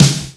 Snares
GVD_snr (22).wav